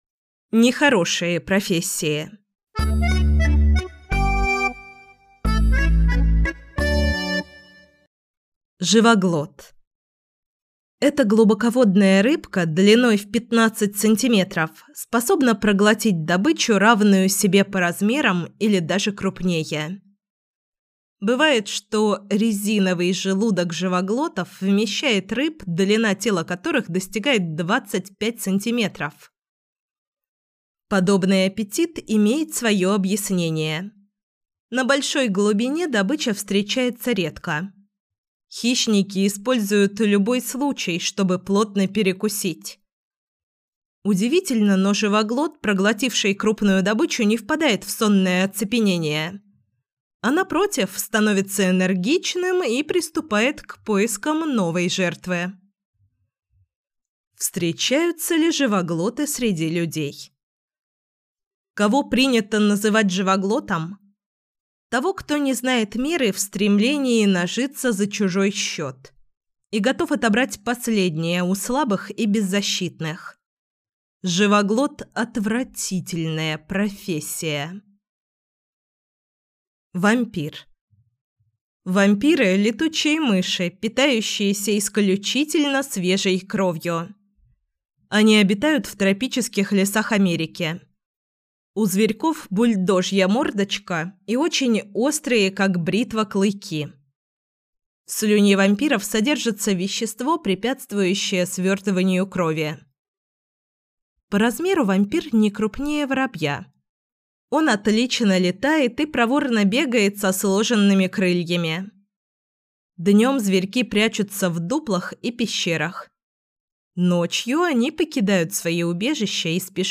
Аудиокнига Аудиоэнциклопедия. Профессии животных | Библиотека аудиокниг